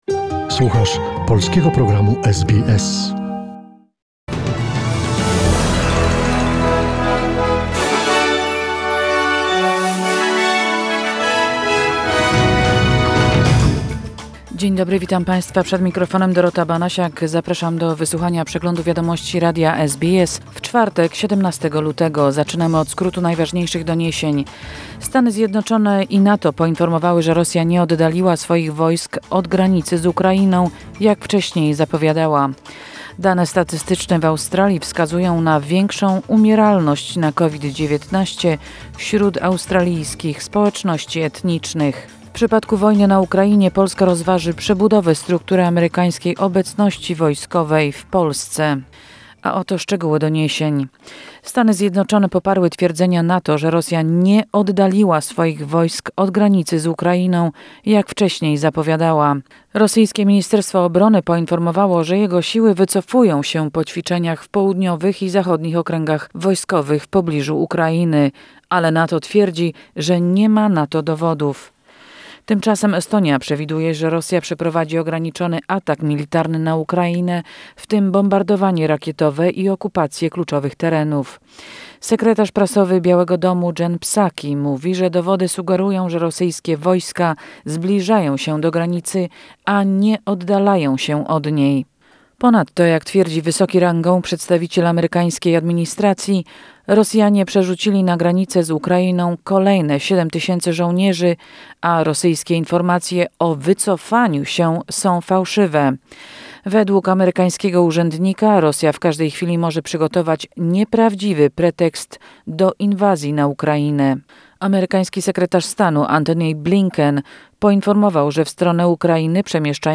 SBS News Flash in Polish, 17 February 2021